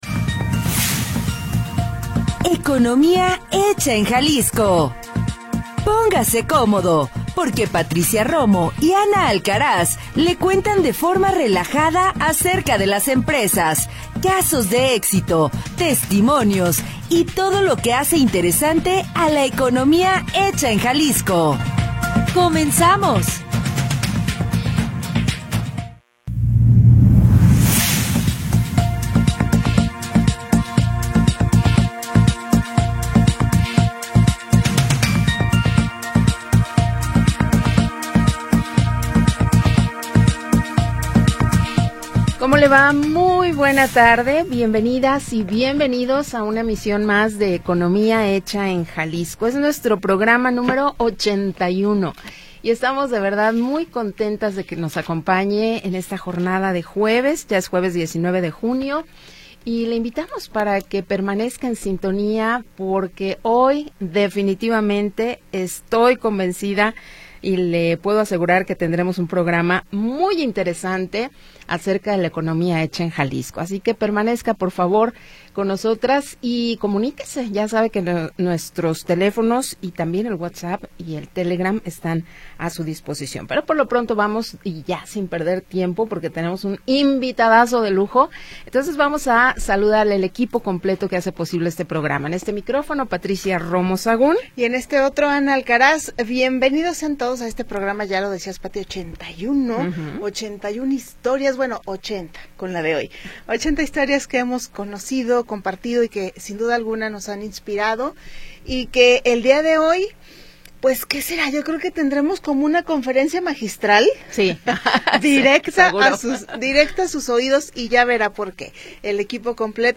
le cuentan de forma relajada acerca de las empresas, casos de éxito, testimonios y todo lo que hace interesante a la economía hecha en Jalisco.
Programa transmitido el 19 de Junio de 2025.